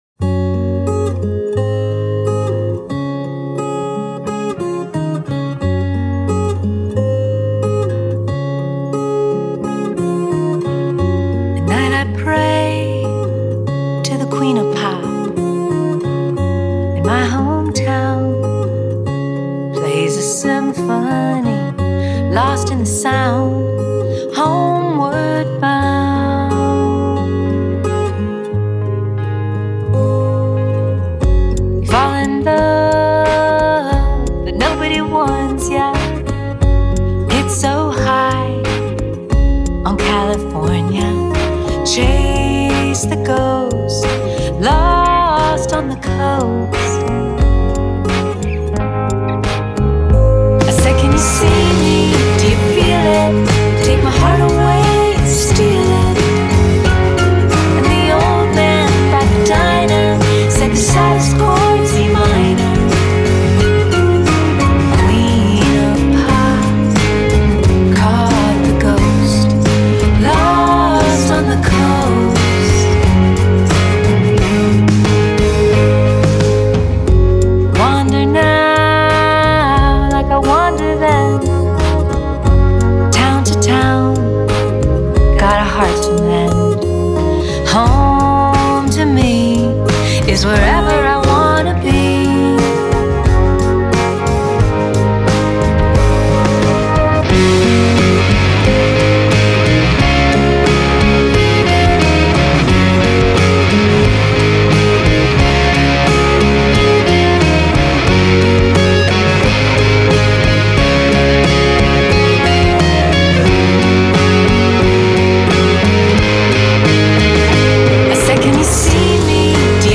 Exquisite singing, memorable melody